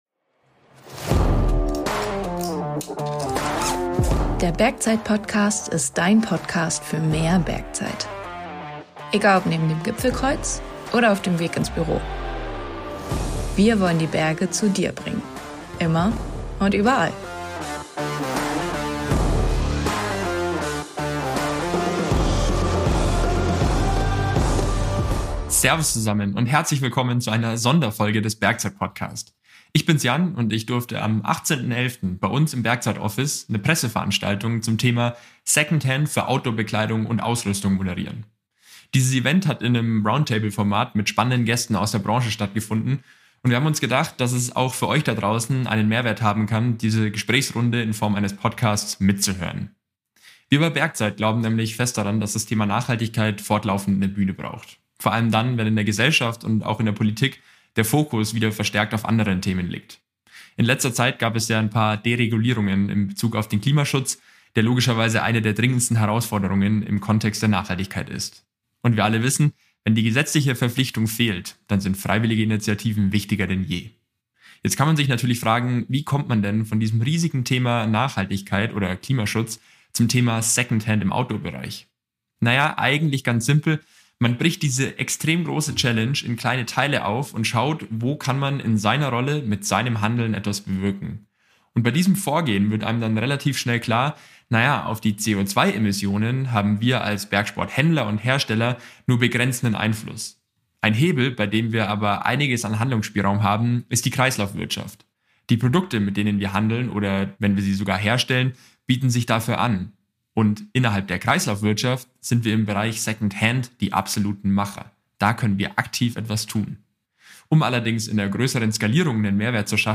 Um die Chancen und Herausforderungen im Outdoor-Segment zu diskutieren, haben wir Vertreter aus der Branche - Globetrotter, Sport Conrad und Patagonia - zu einer Gesprächsrunde an unseren Bergzeit Hauptsitz in Otterfing eingeladen. Unser Ziel: Erfahrungen austauschen, voneinander lernen und gemeinsam Lösungen für die Kreislaufwirtschaft entwickeln.